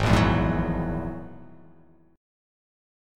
Ab7sus2#5 chord